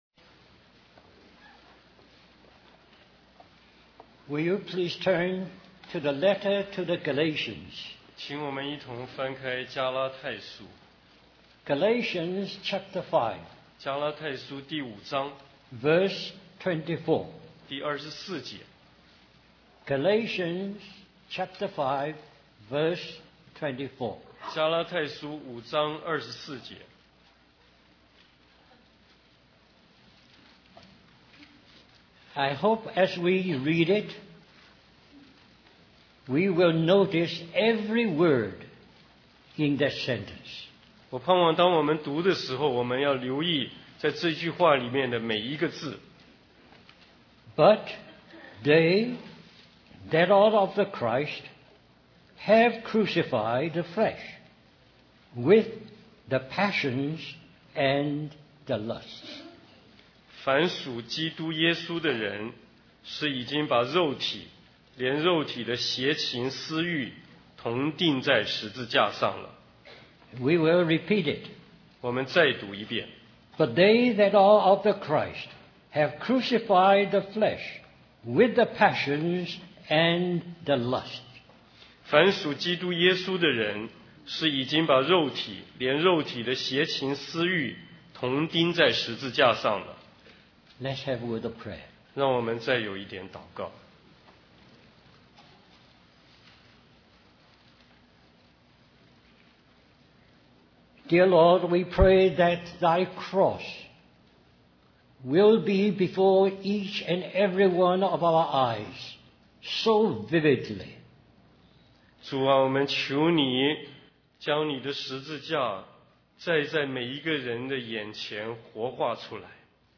In this sermon, the preacher discusses the concept of living according to the flesh versus living according to the spirit. He uses the illustration of grafting to explain how our sinful nature can be transformed through faith in Jesus.